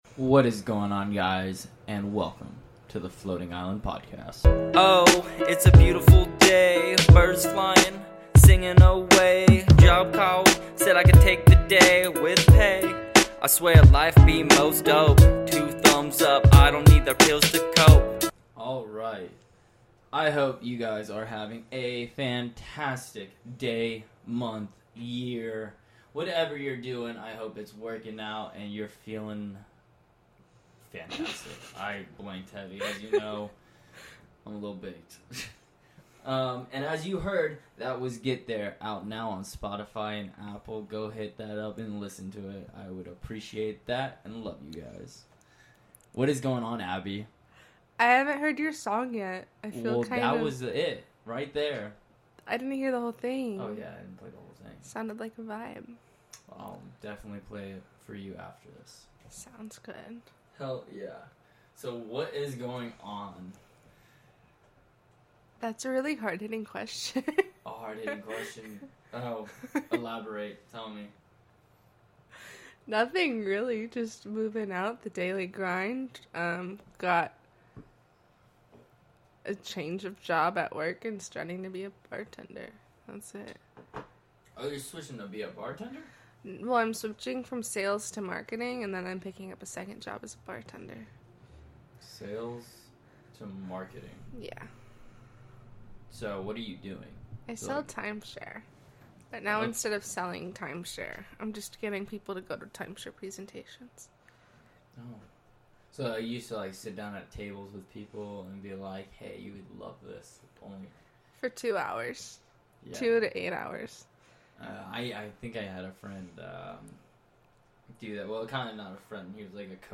conspiracy filled conversation!!